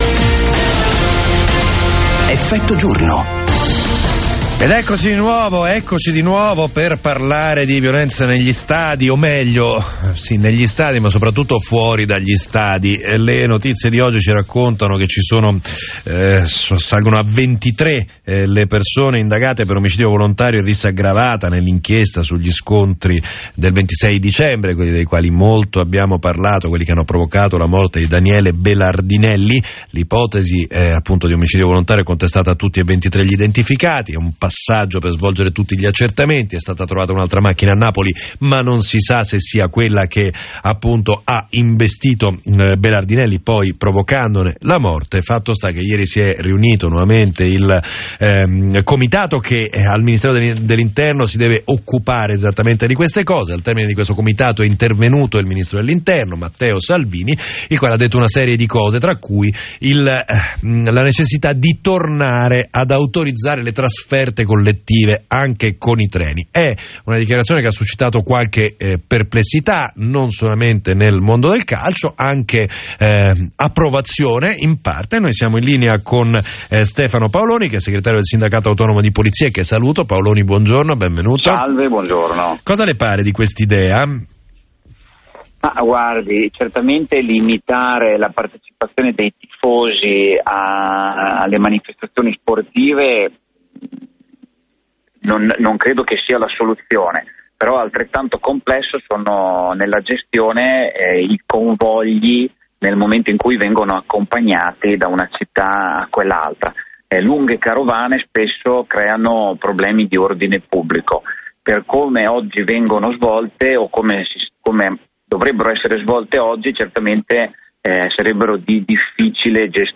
Radio24 chiede il parere del Sindacato Autonomo di Polizia con una intervista